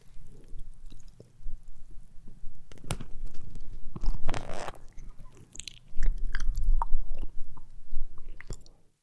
Звук поедания сырого томата у микрофона